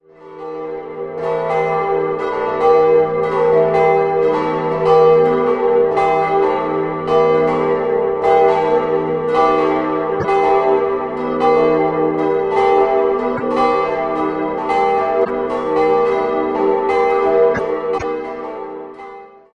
4-stimmiges ausgefülltes E-Moll-Geläute: e'-g'-a'-h' Die Glocken 2, 3 und 4 wurden 1964 von Friedrich Wilhelm Schilling in Heidelberg gegossen, die große Glocke ist ein wertvolles Exemplar von Christoph Glockengießer (Nürnberg) aus dem Jahr 1564.